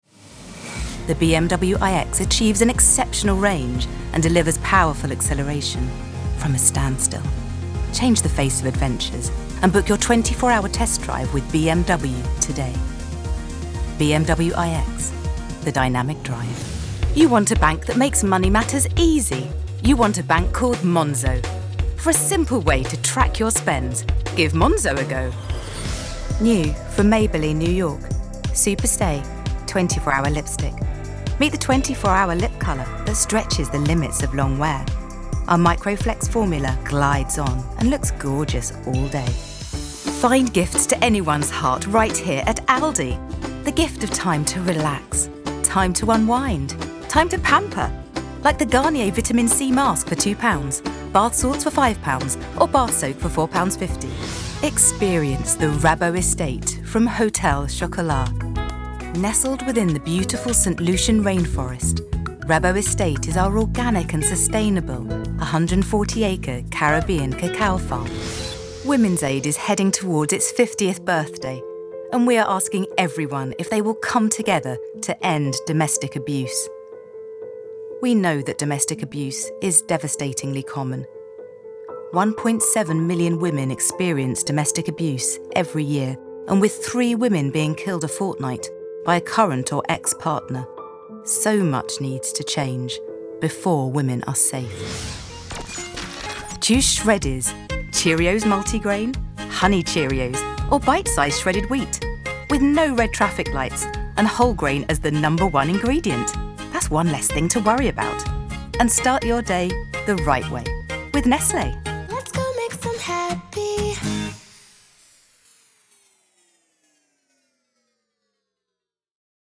British female VO with warm, confident style
Commercial Demo
English RP, London, Bristol
Middle Aged